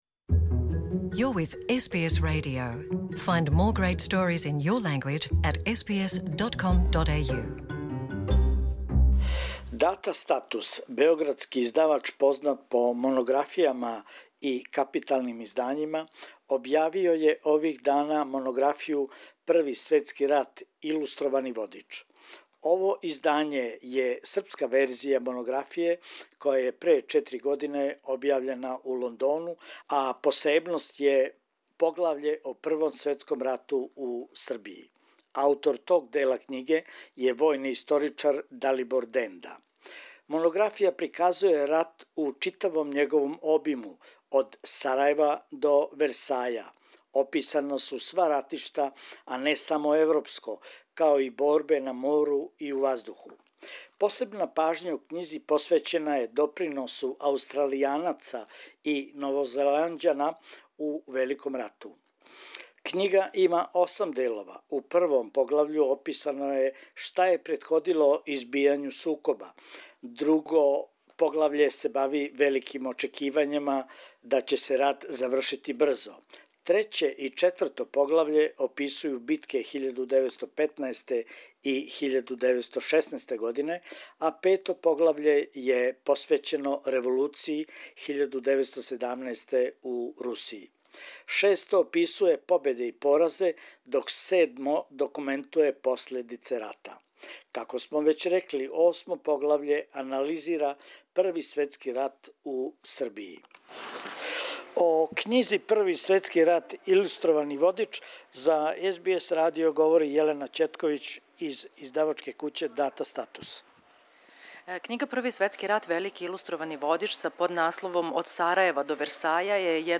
прочитала је и једно писмо аустралијског војника упућено породици